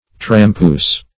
Search Result for " trampoose" : The Collaborative International Dictionary of English v.0.48: Trampoose \Tram*poose"\, v. i. [See Tramp , Trample , and Traipse .] To walk with labor, or heavily; to tramp.